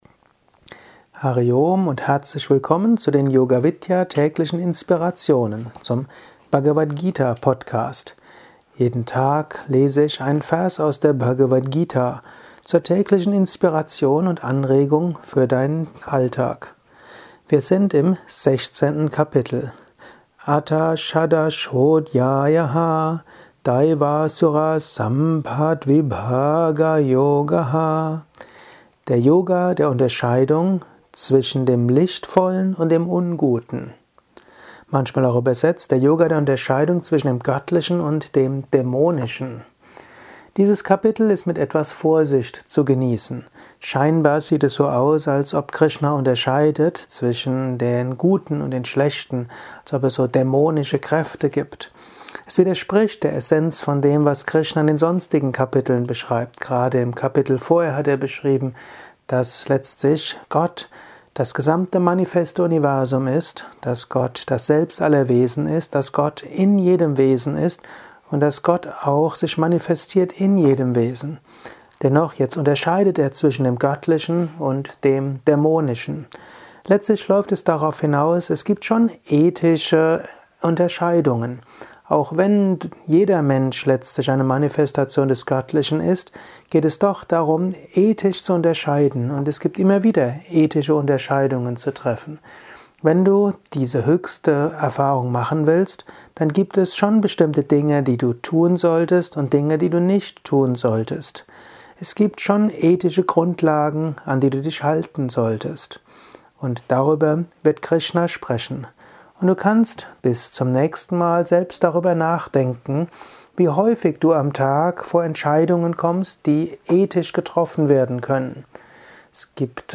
Dies ist ein kurzer Kommentar als Inspiration für den heutigen Tag